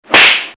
巴掌声.MP3